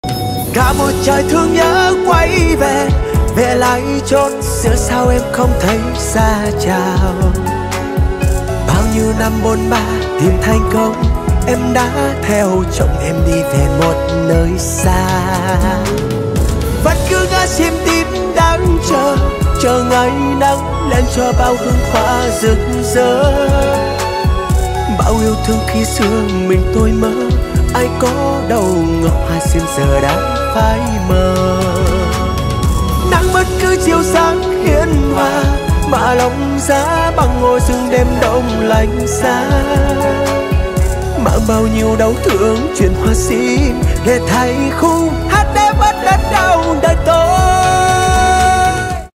Nhạc chuông gợi nhớ kỷ niệm buồn man mác